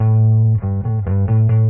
描述：电贝司
Tag: 贝司